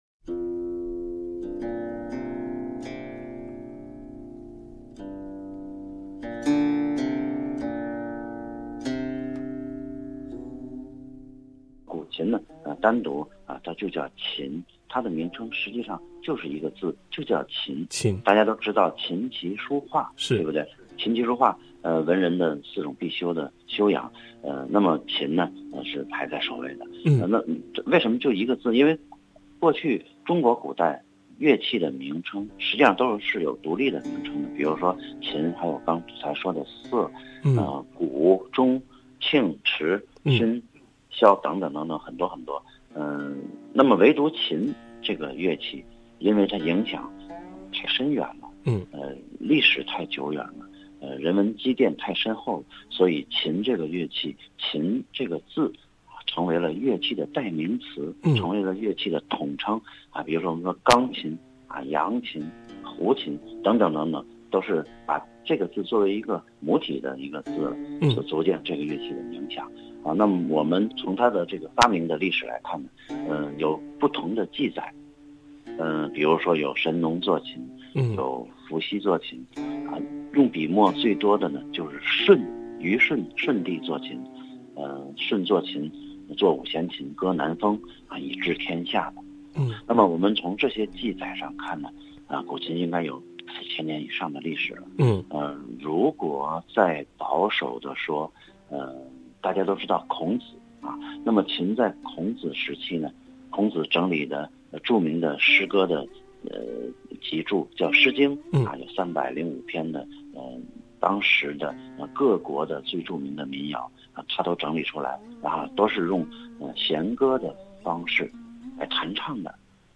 SBS Mandarin